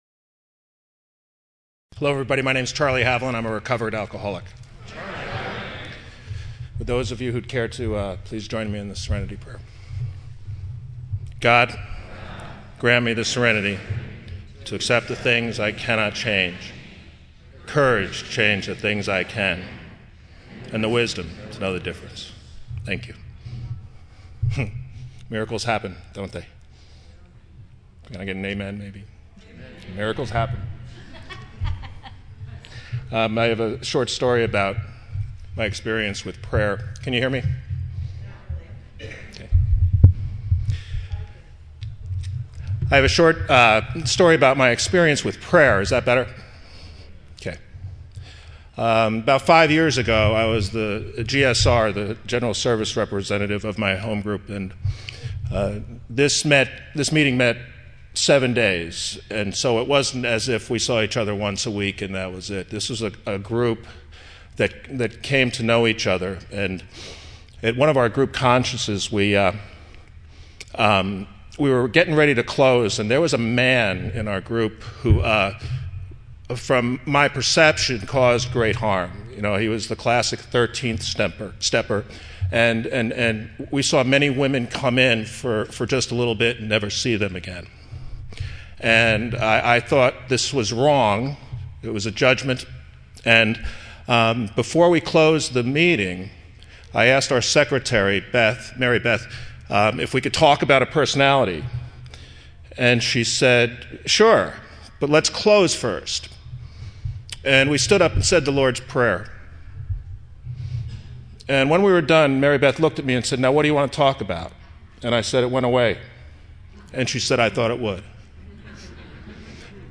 San Diego Spring Roundup 2012